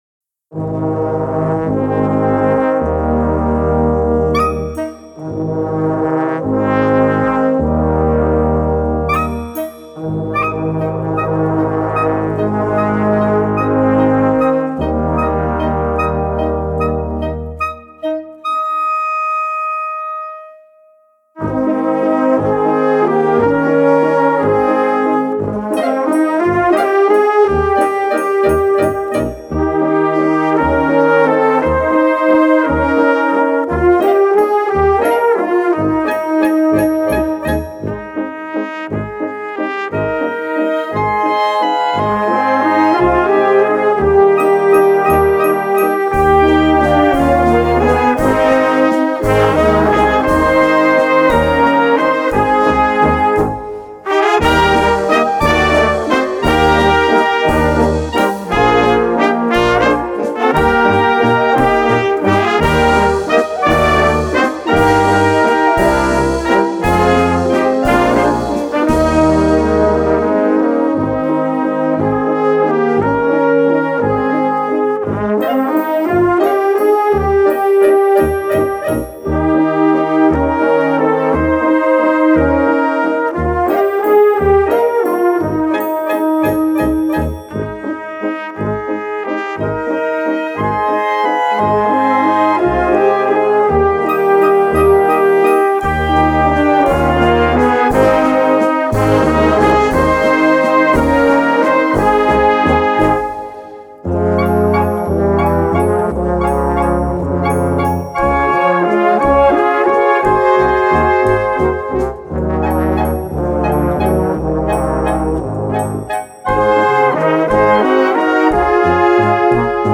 Catégorie Harmonie/Fanfare/Brass-band
Sous-catégorie Valses
Instrumentation Ha (orchestre d'harmonie)